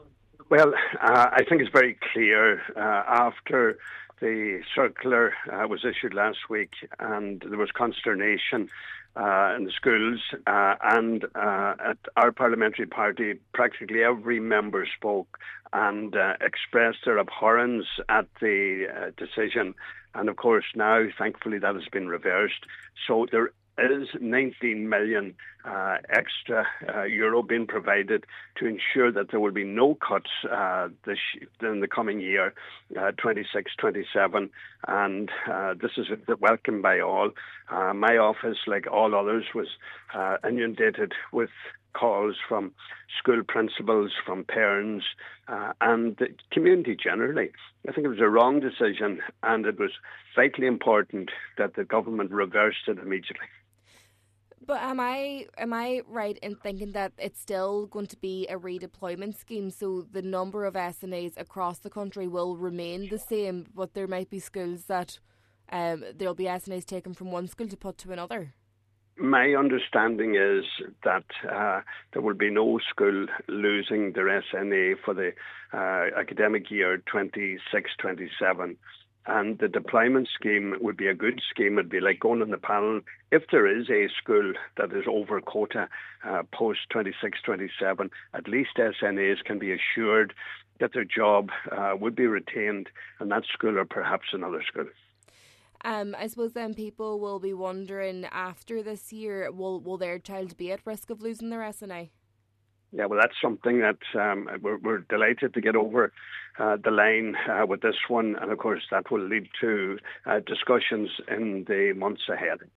Donegal Deputy Pat the Cope Gallagher says for the incoming academic year, there will be no changes: